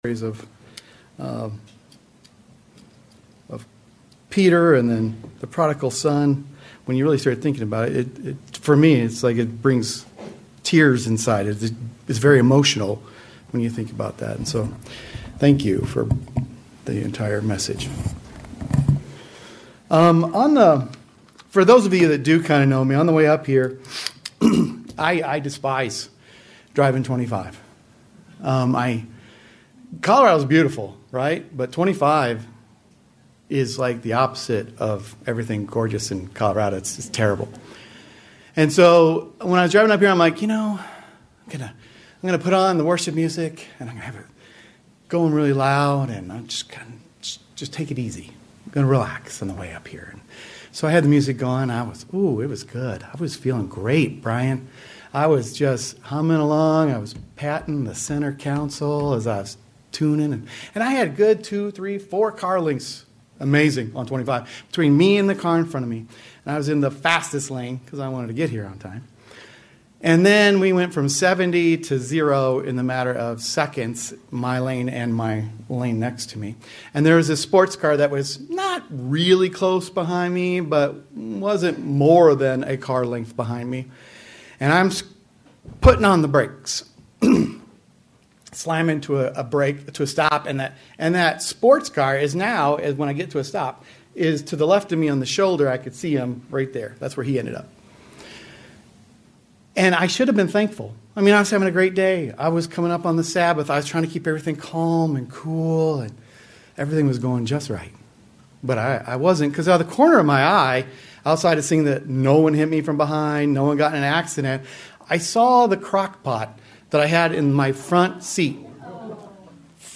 Sermons
Given in Denver, CO Colorado Springs, CO Loveland, CO